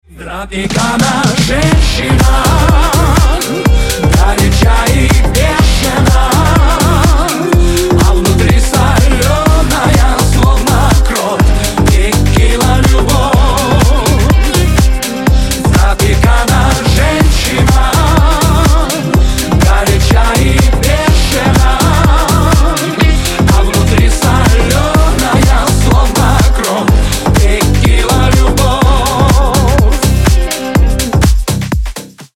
• Качество: 320, Stereo
Club House
ремиксы